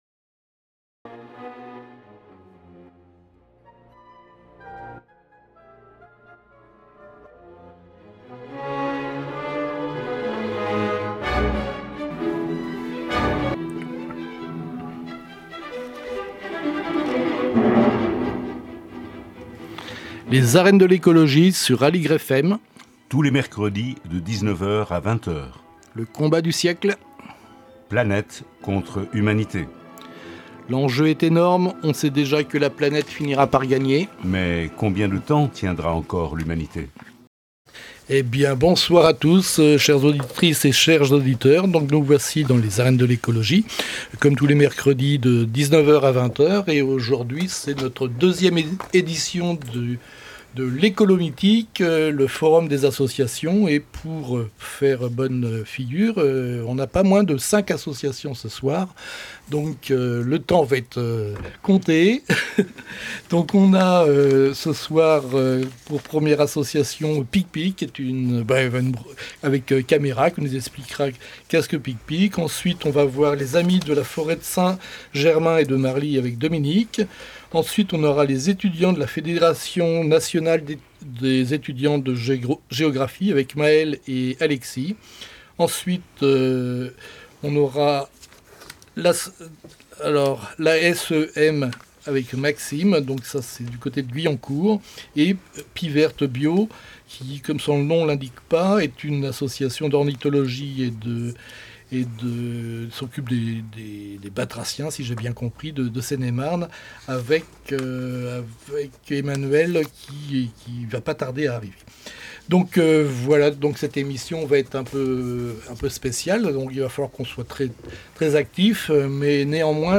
Des voix engagées qui mêlent pédagogie, plaidoyer et mobilisation collective.